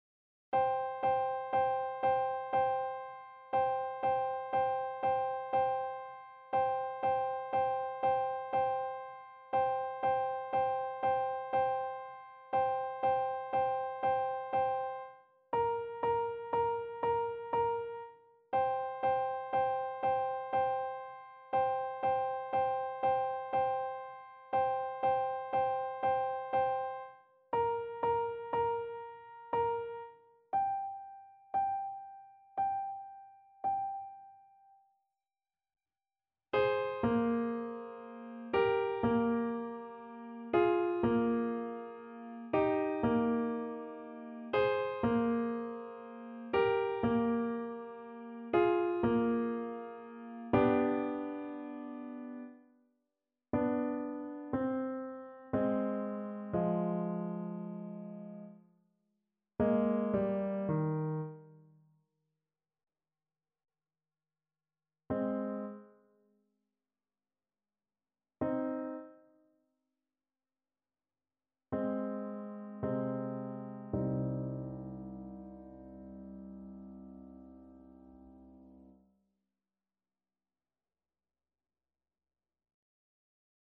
Play (or use space bar on your keyboard) Pause Music Playalong - Piano Accompaniment Playalong Band Accompaniment not yet available transpose reset tempo print settings full screen
C minor (Sounding Pitch) D minor (Clarinet in Bb) (View more C minor Music for Clarinet )
3/4 (View more 3/4 Music)
Andante sostenuto =60
Classical (View more Classical Clarinet Music)